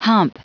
Prononciation du mot hump en anglais (fichier audio)
Prononciation du mot : hump